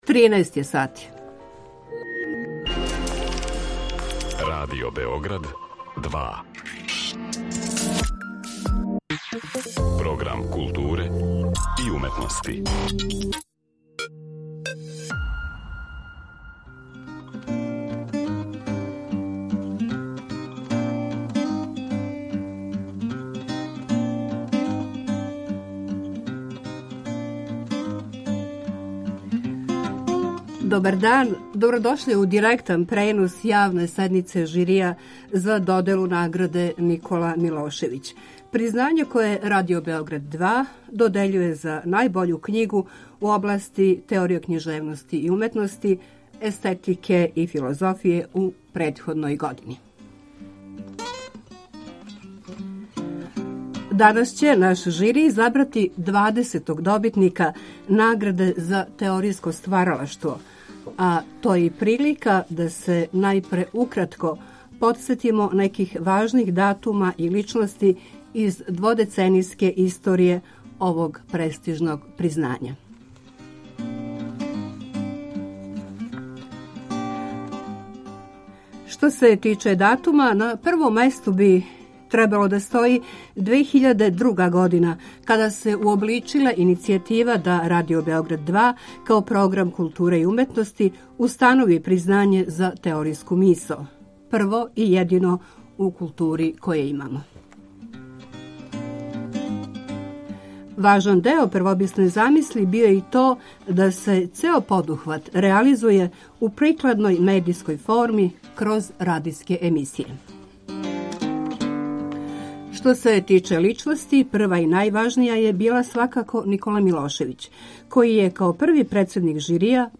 Завршну седницу жирија Радио Београд 2 директно ће преносити од 13 сати.